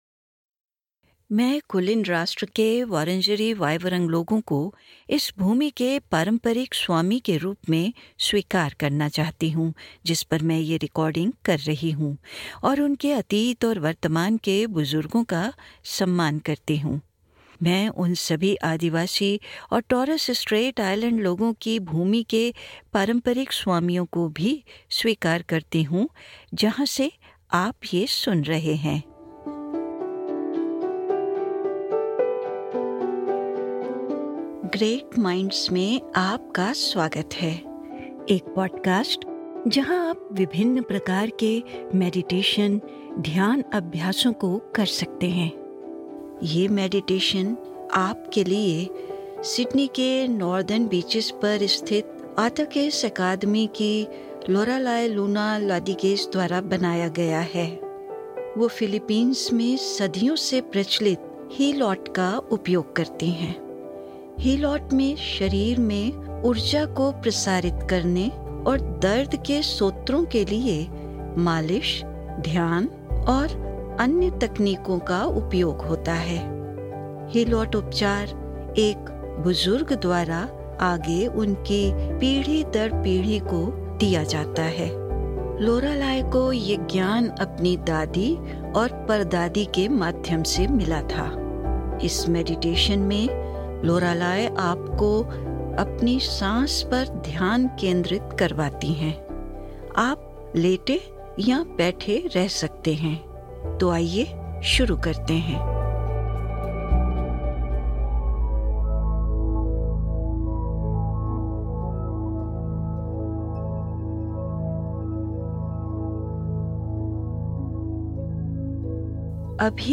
हिलोट, फिलीपींस की एक प्राचीन प्रथा है। यह मैडिटेशन सांस लेने का एक सरल व्यायाम है, जिसमें आप धीरे-धीरे अपने हृदय पर केंद्रित करते हैं।